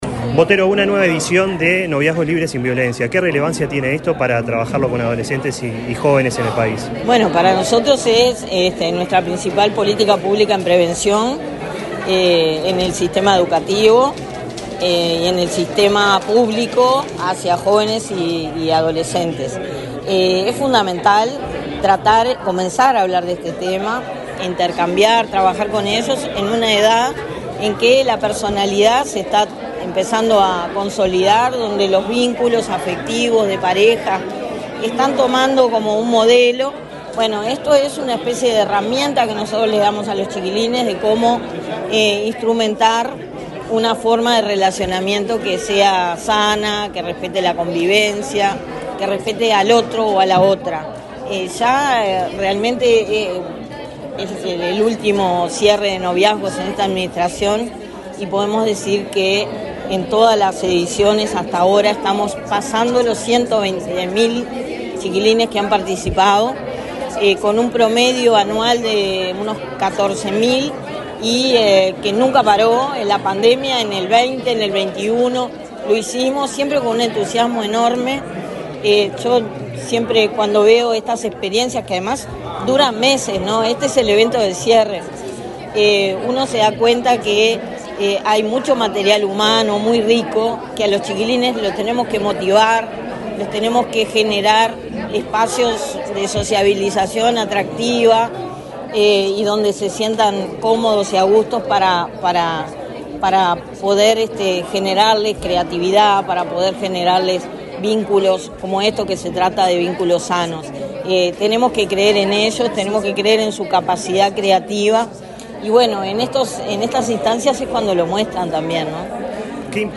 Entrevista a la directora de Inmujeres, Mónica Bottero
Este lunes 30 en Montevideo, la directora del Instituto Nacional de las Mujeres (Inmujeres), Mónica Bottero, dialogó con Comunicación Presidencial,